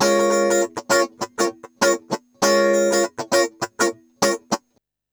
100FUNKY03-L.wav